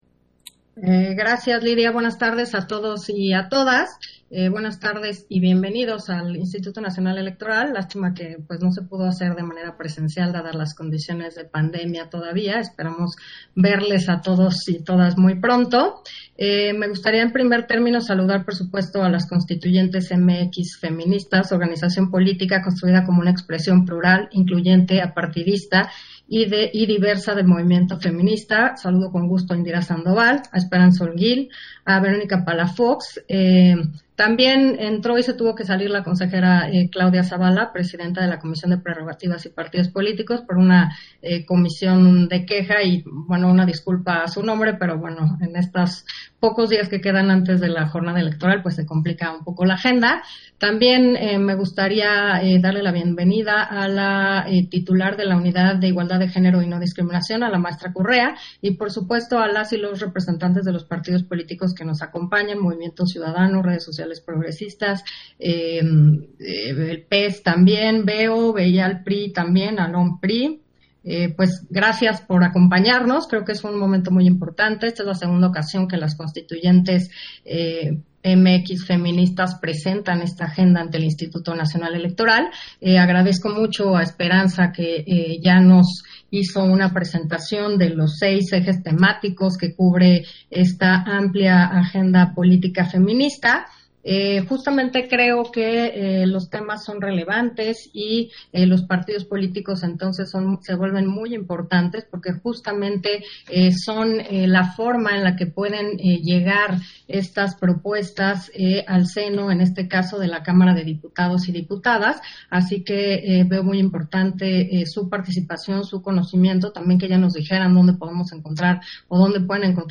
Intervención de Carla Humphrey, en la presentación de la Agenda Política Feminista Federal 2021